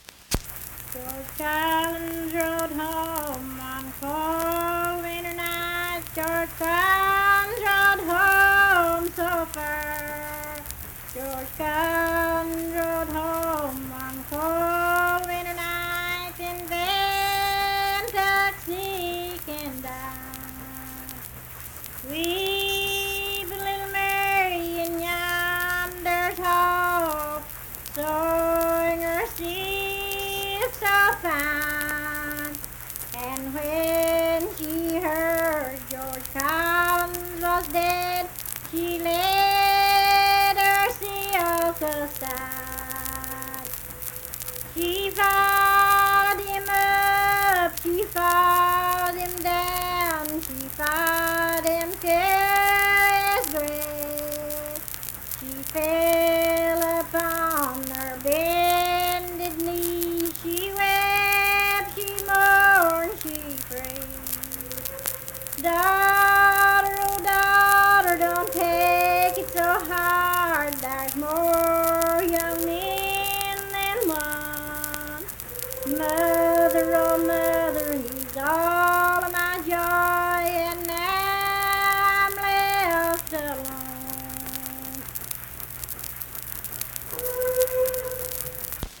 Unaccompanied vocal music
Verse-refrain, 4(4).
Voice (sung)